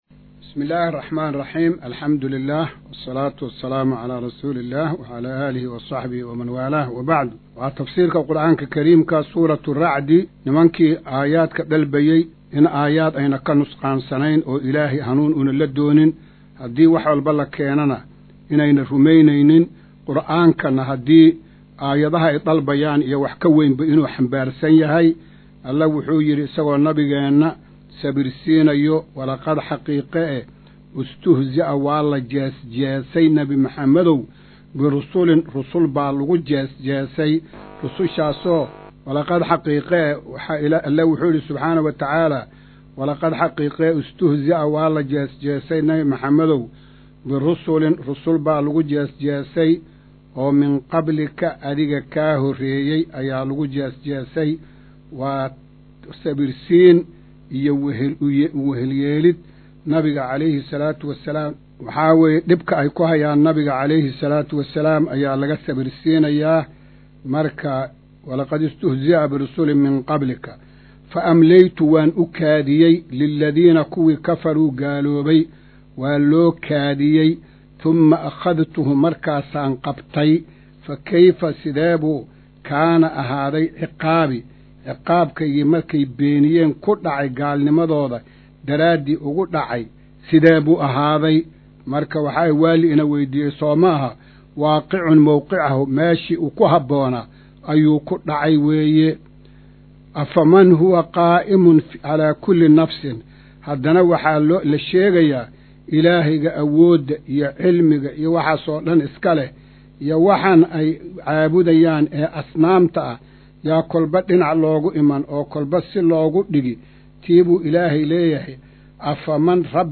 Maqal:- Casharka Tafsiirka Qur’aanka Idaacadda Himilo “Darsiga 125aad”